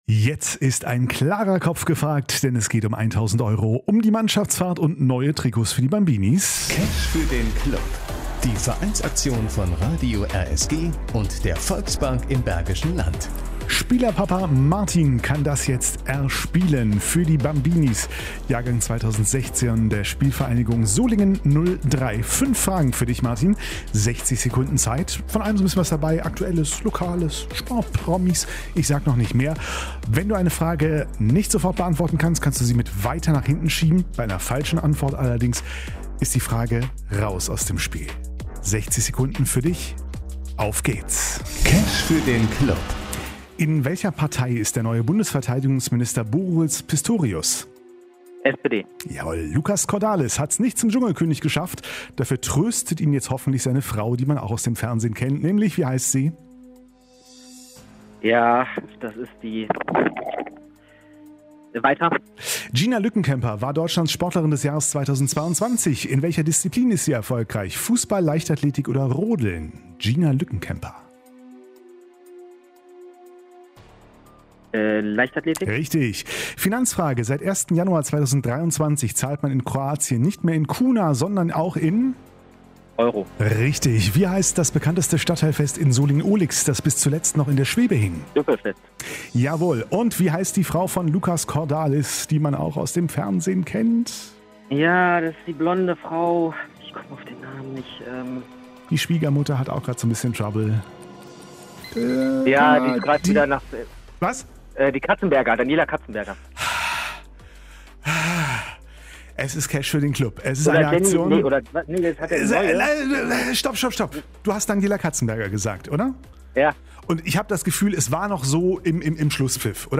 Cash für den Club 2023Spvg. Solingen 03 G1 - Quiz
cash_quiz_solingen_03.mp3